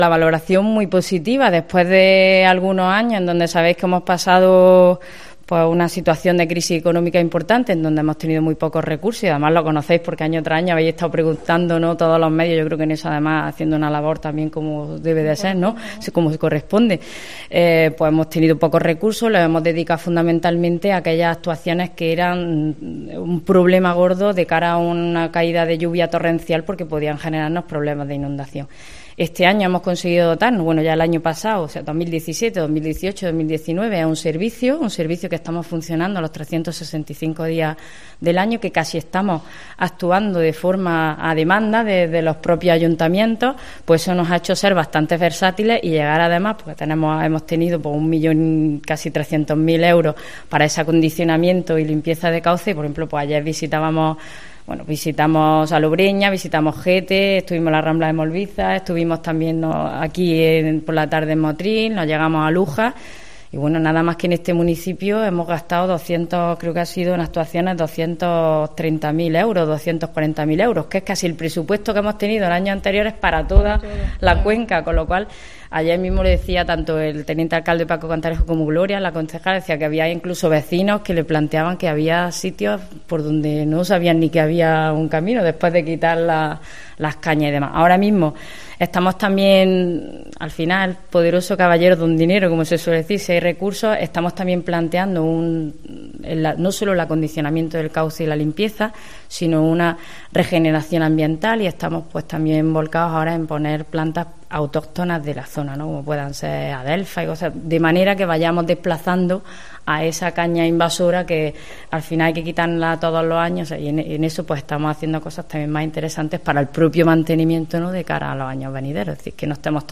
AUDIO: La delegada de Medio Ambiente en Granada, Inma Oria, valora positivamente la visita ayer a la limpieza de los cauces de la Costa Tropical.